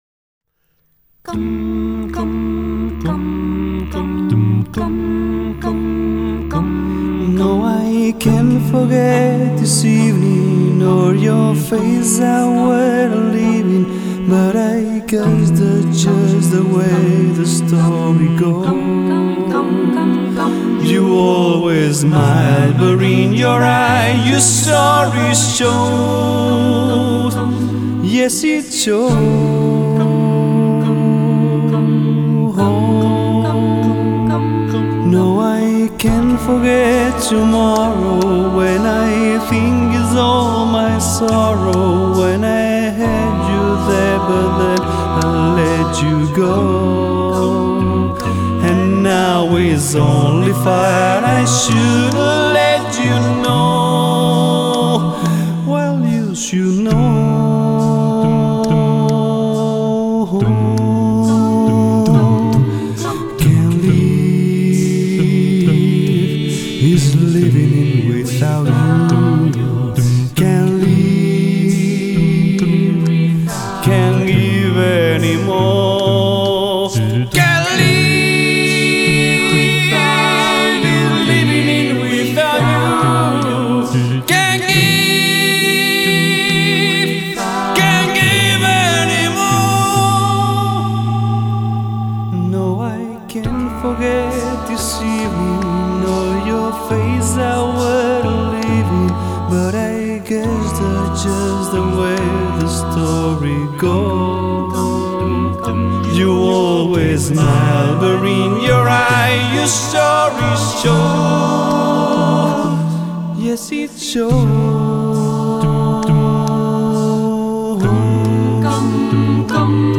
a vocal band